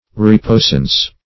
reposance - definition of reposance - synonyms, pronunciation, spelling from Free Dictionary Search Result for " reposance" : The Collaborative International Dictionary of English v.0.48: Reposance \Re*pos"ance\ (-ans), n. Reliance.